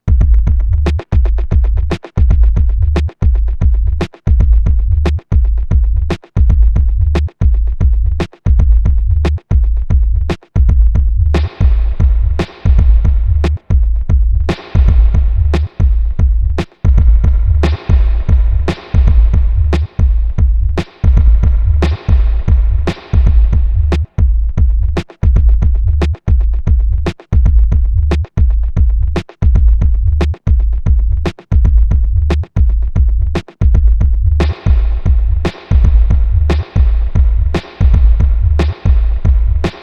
RIDDIM LOOP
boomarmriddim.aif